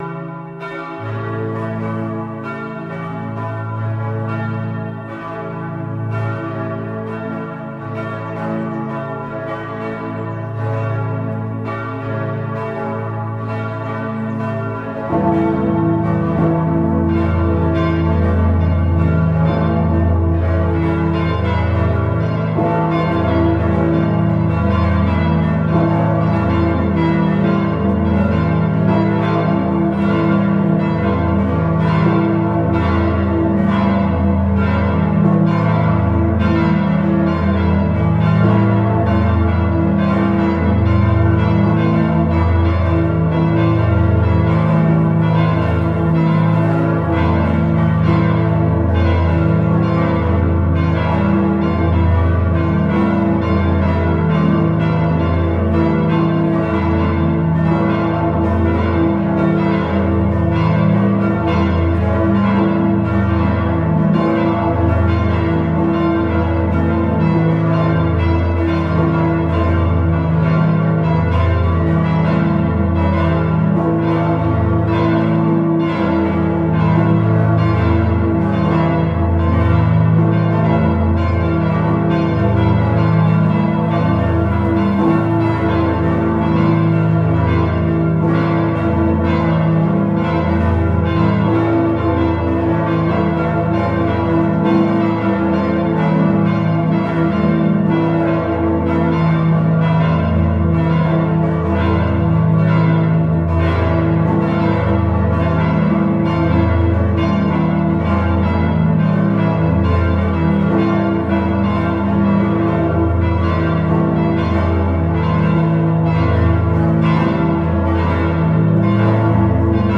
צלילי הפעמונים מעל העיר רומא
St.-Peter-Basilica-ROME-11-.mp3